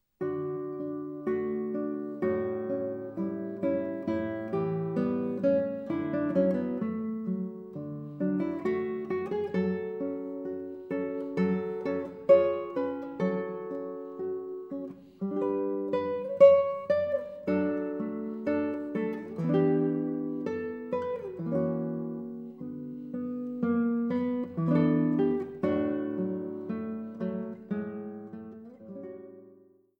für Sprecher und Gitarre op. 190
Gitarre